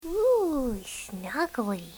I've also done Kiki sound bites (those of my friends who've read the strip --still trying to recruit more--
think that I have a very convincing Kiki-voice).